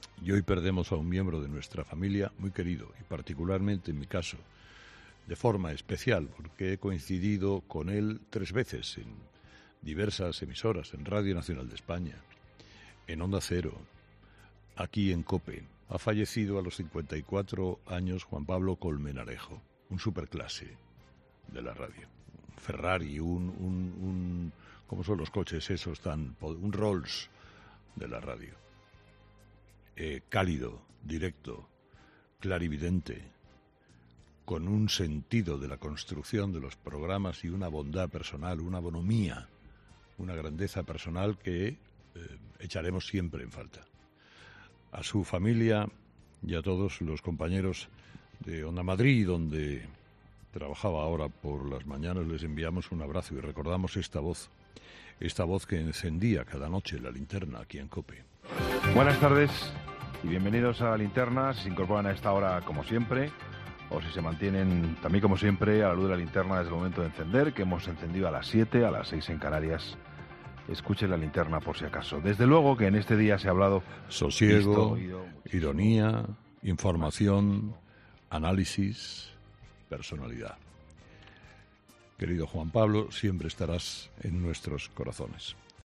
Es el caso de Carlos Herrera quien le ha lamentado su pérdida este jueves desde los micrófonos de 'Herrera en COPE'.